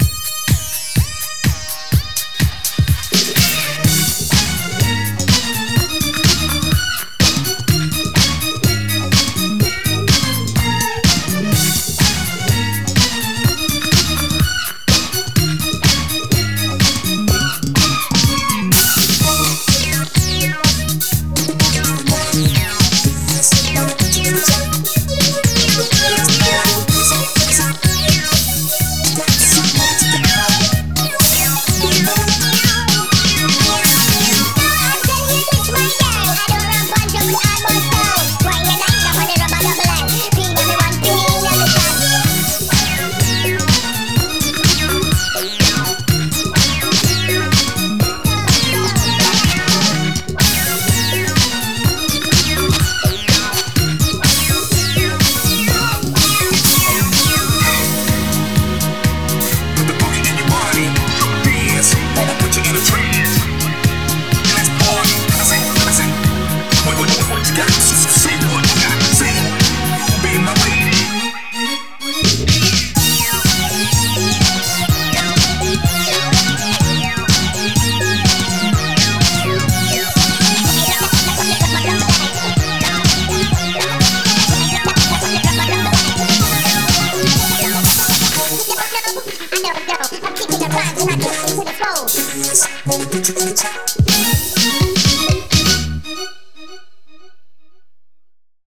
BPM125
Better quality audio.